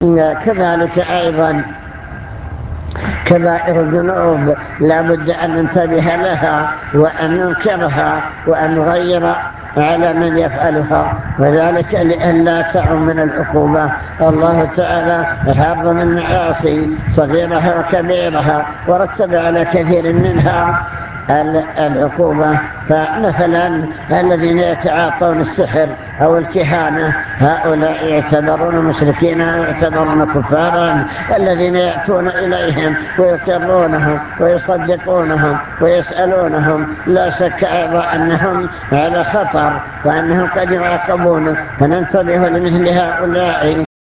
المكتبة الصوتية  تسجيلات - محاضرات ودروس  محاضرة بعنوان من يرد الله به خيرا يفقهه في الدين التحذير من بعض المنكرات